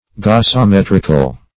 Search Result for " gasometrical" : The Collaborative International Dictionary of English v.0.48: Gasometric \Gas`o*met"ric\ (? or ?), Gasometrical \Gas`o*met"ric*al\, a. Of or pertaining to the measurement of gases; as, gasometric analysis.